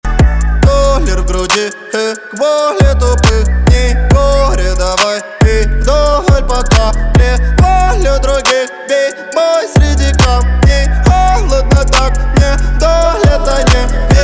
• Качество: 192, Stereo
мужской голос
громкие
русский рэп
качающие